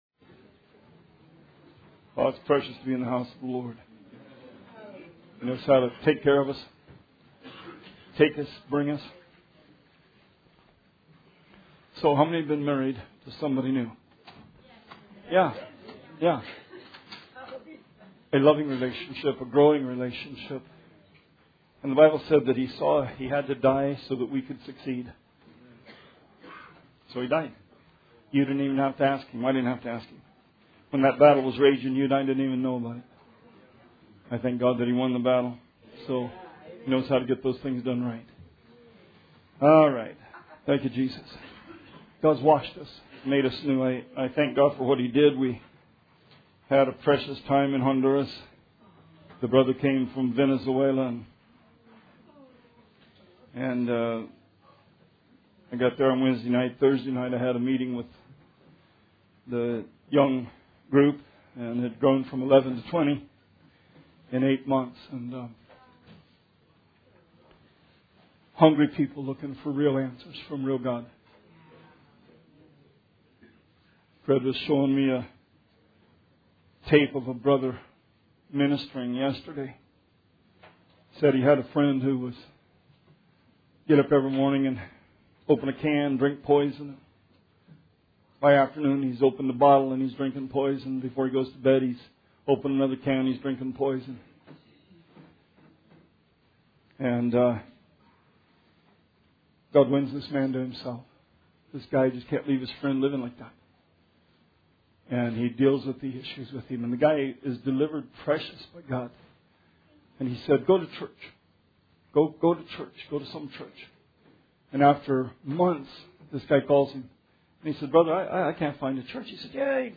Sermon 3/19/17